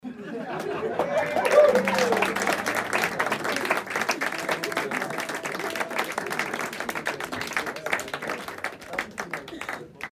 Royalty free sounds: Concert and theater
mf_SE-4285-applause_with_laugh_5.mp3